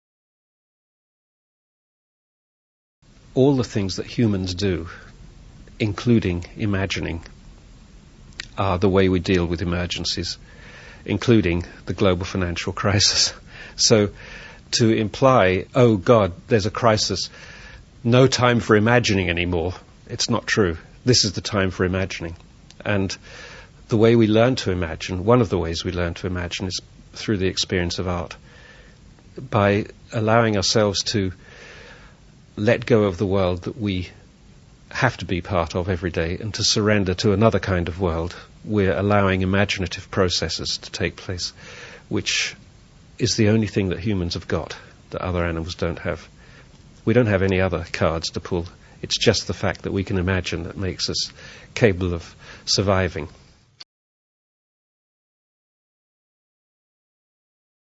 I caught this tiny snippet of an interview with Brian Eno on BBC Radio 4 this morning.